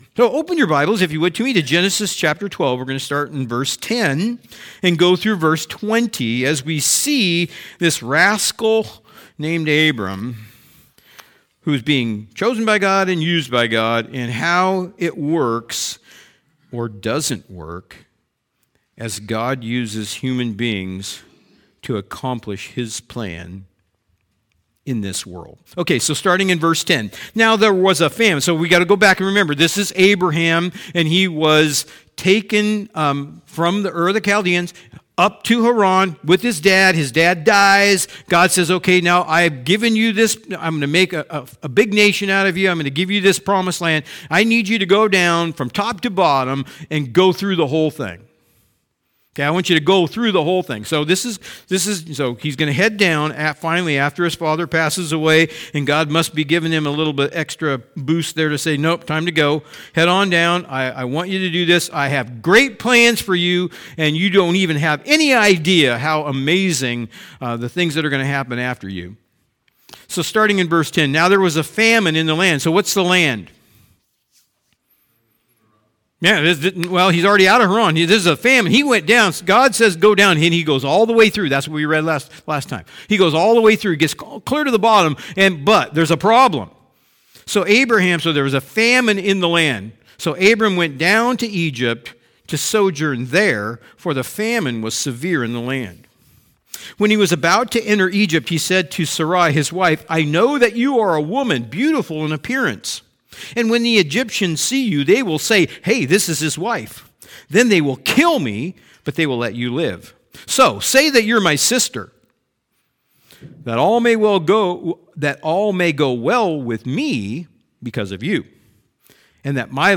Sermons | Machias Community Church